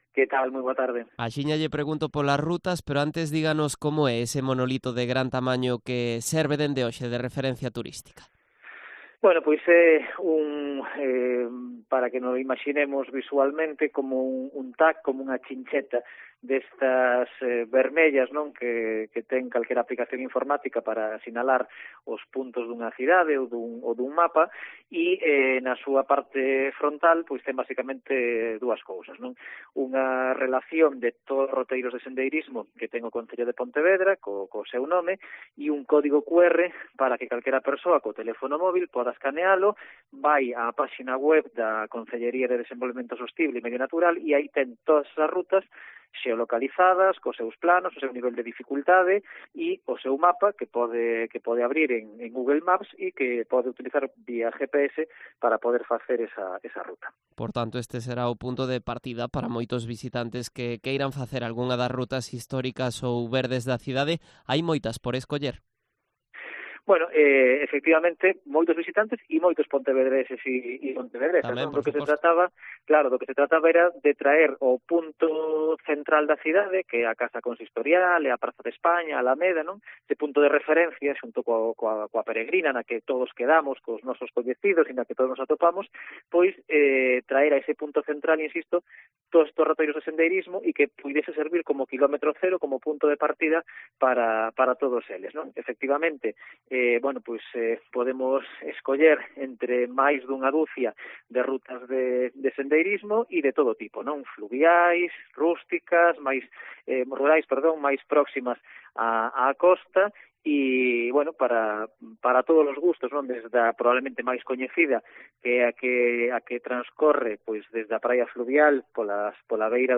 Entrevista a Iván Puentes, concelleiro de Desenvolvemento Sostible e Medio Natural